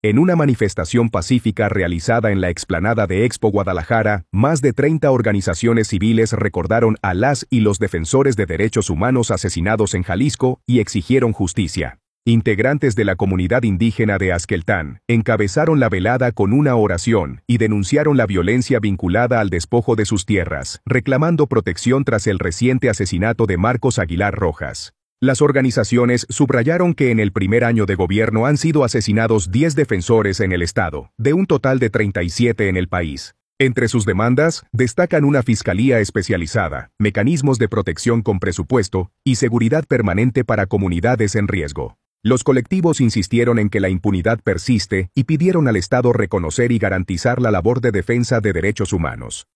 En una manifestación pacífica realizada en la explanada de Expo Guadalajara, más de 30 organizaciones civiles recordaron a las y los defensores de derechos humanos asesinados en Jalisco y exigieron justicia.